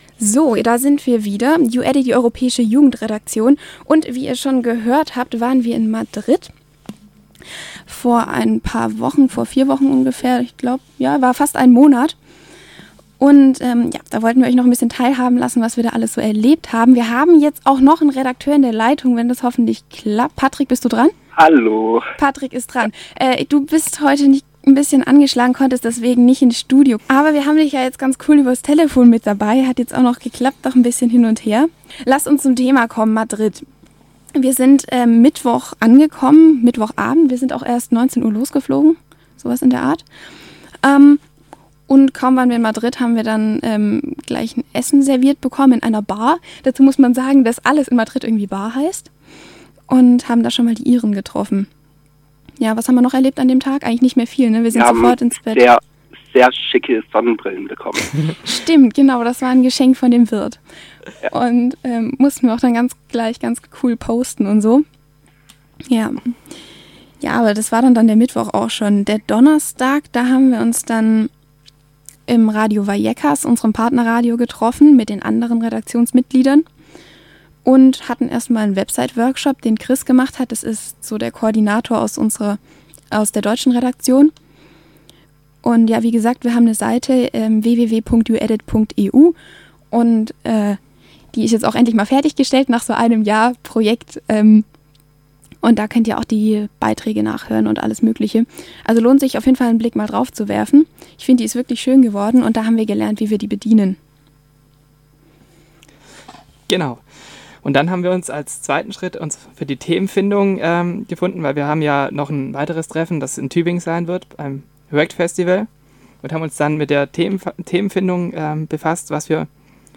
Bericht über das 3. YouEdiT-Meeting in Madrid